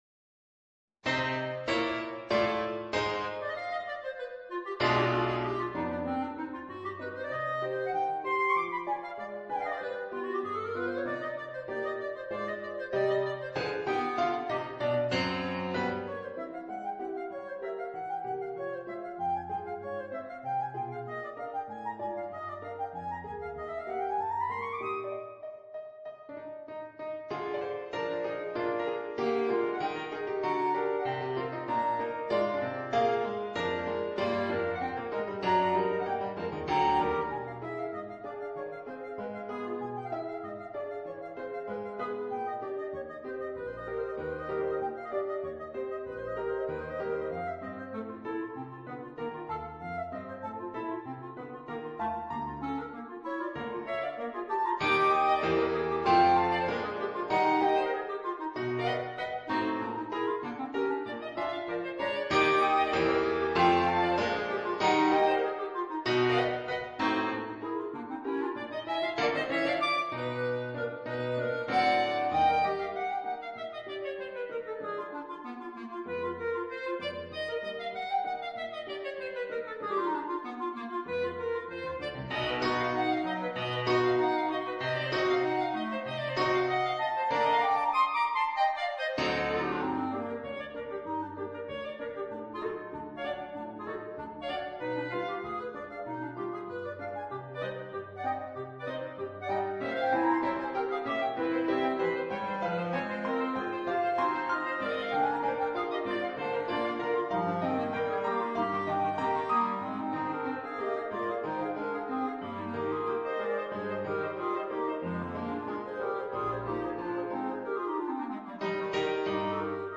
per 2 clarinetti e pianoforte